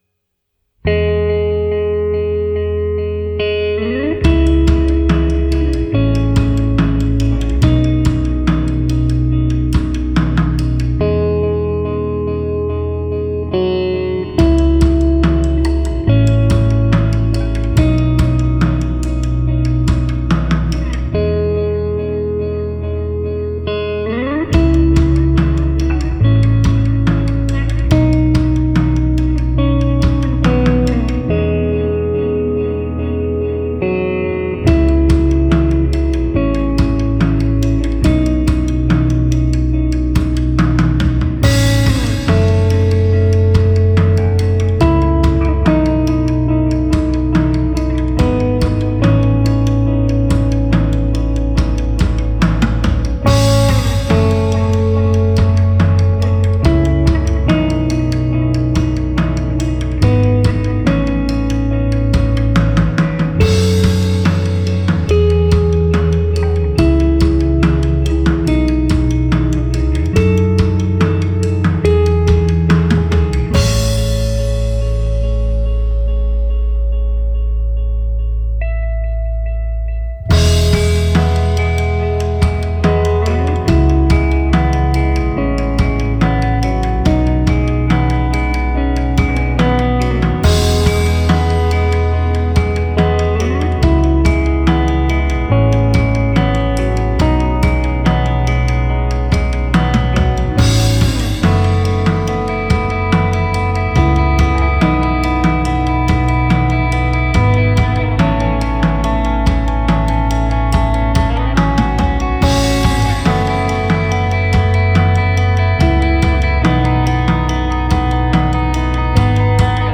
post rock assez classique